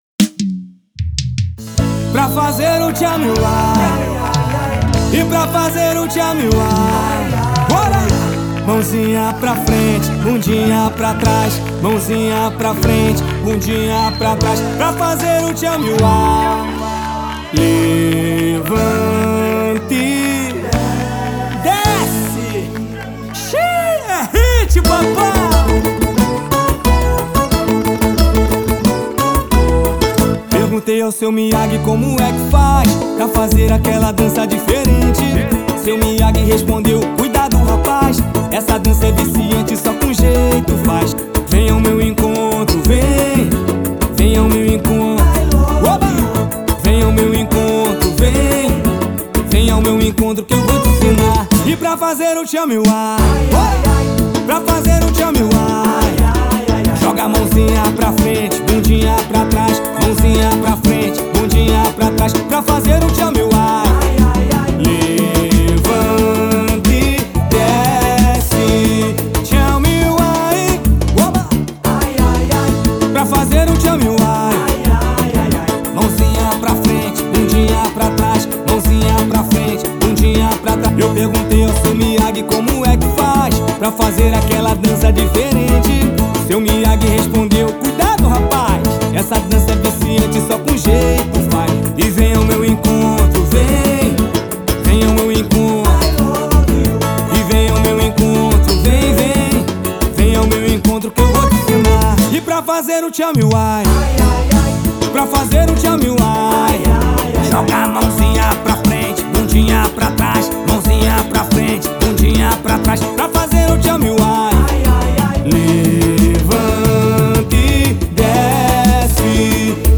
versão brasileira do sucesso gringo.
com batida leve e animada para não deixar ninguém parado  “